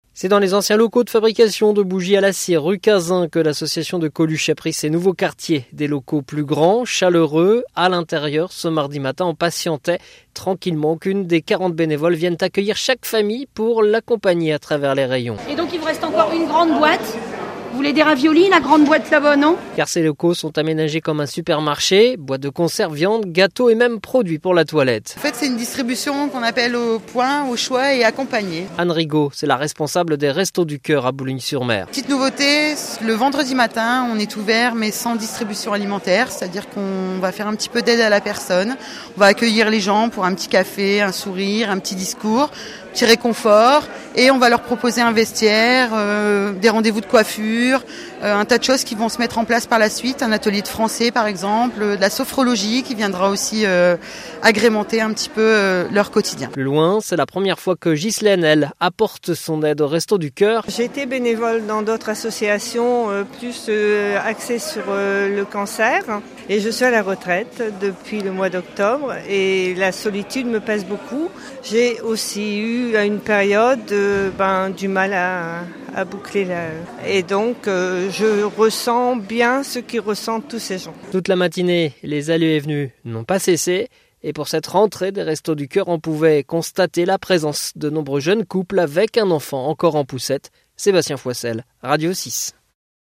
500 familles sont accueilli dans des nouveaux locaux avec une distribution très moderne façon supermarché. reportage